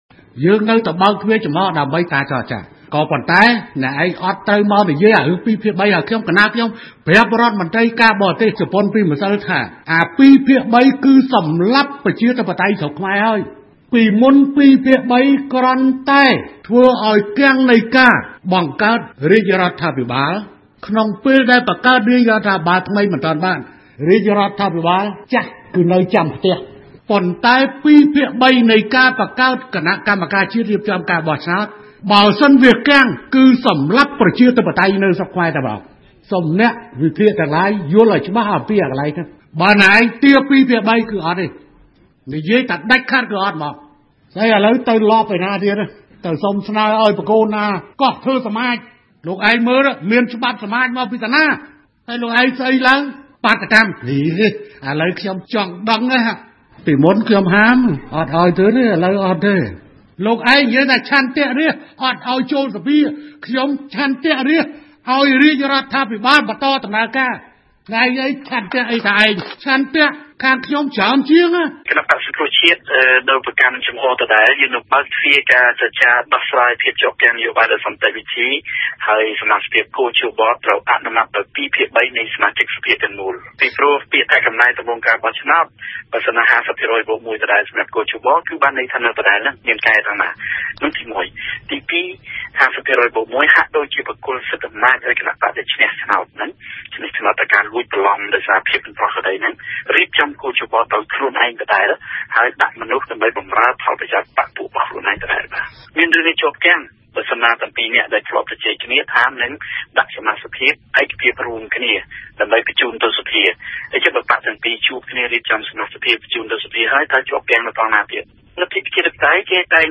ប្រសាសន៍ របស់ លោកនាយក រដ្ឋមន្ត្រី ហ៊ុន សែន និងលោក យ៉ែម ប៉ុញ្ញឫទ្ធិ